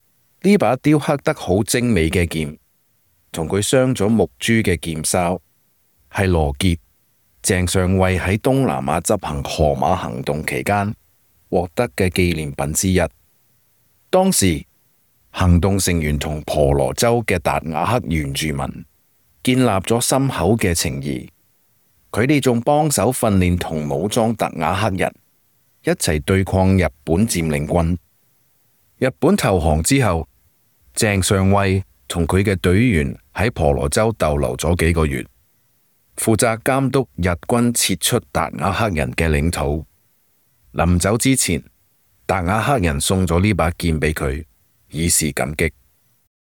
Special Operations Voiceovers
2_CANTO_Dayak_Sword_-_Cantonese_Voiceover__eq_.mp3